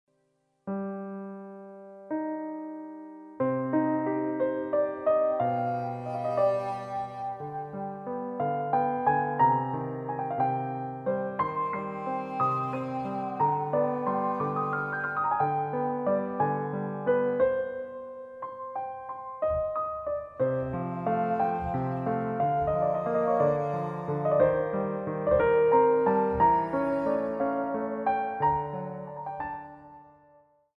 intimate score
on guitar